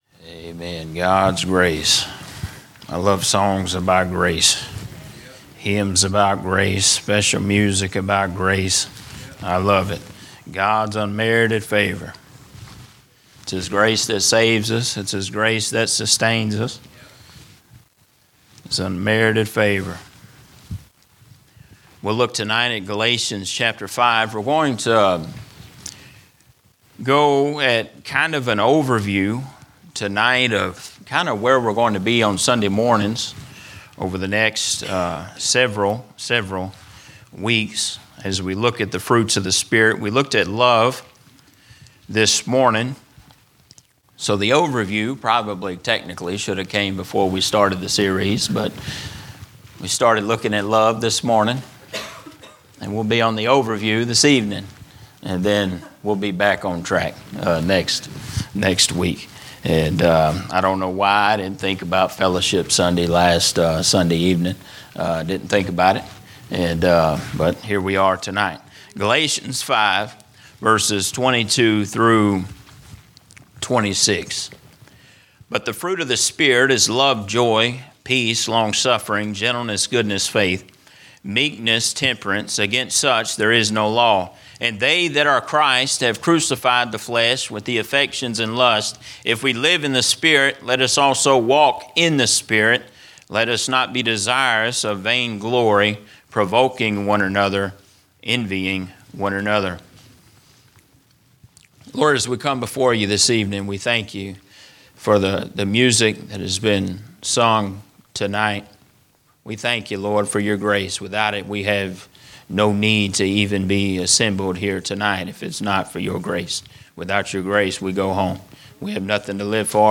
A message from the series "The Fruit of the Spirit." In a world that demands instant relief and quick escapes, the biblical concept of longsuffering often feels like a lost art.